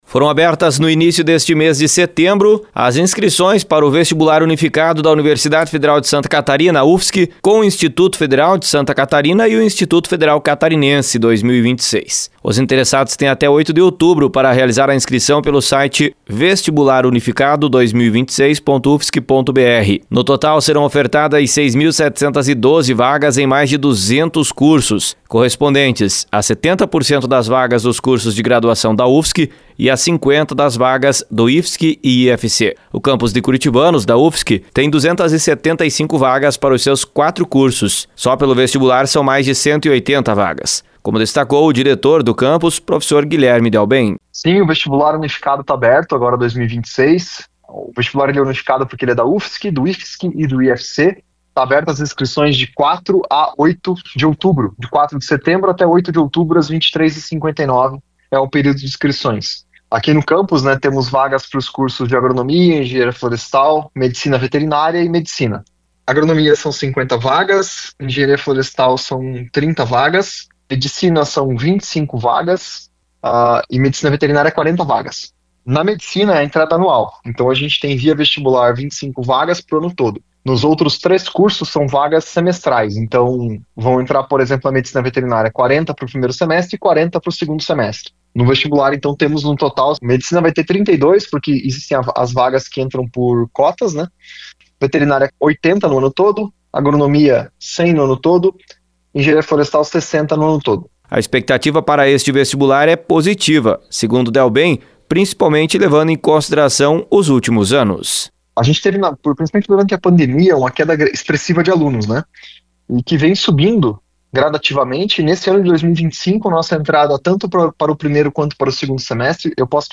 Informações com repórter